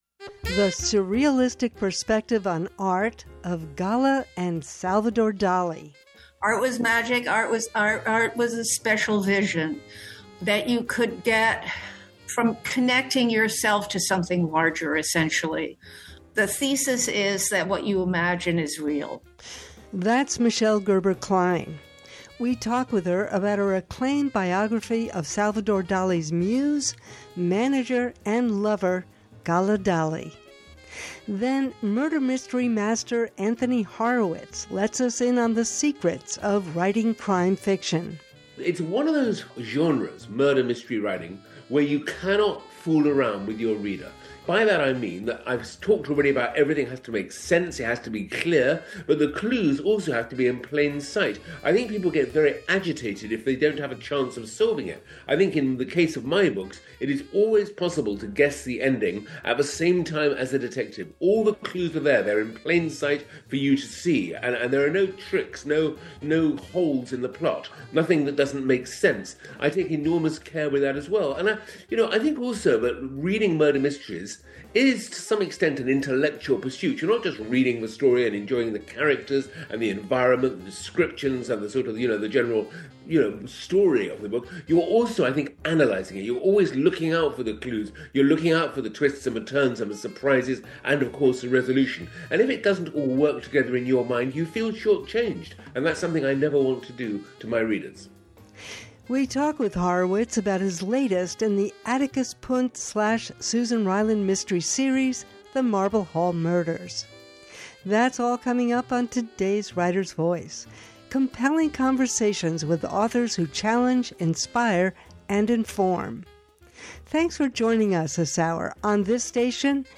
Writer’s Voice: compelling conversations with authors who challenge, inspire, and inform.